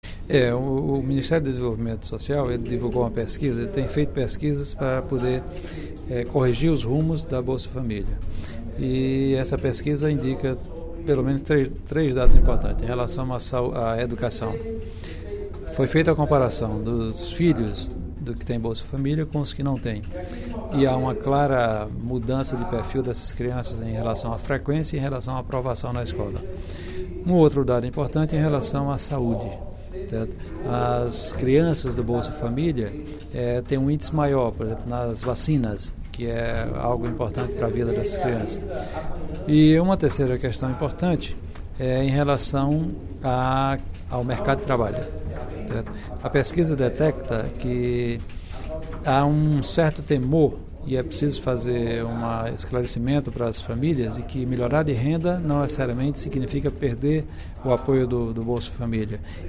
O deputado Professor Pinheiro (PT) destacou nesta sexta-feira (29/06), na Assembleia Legislativa, pesquisa publicada pelo Governo Federal sobre os impactos positivos do Programa Bolsa Família na vida de crianças, principalmente nas áreas de educação e saúde.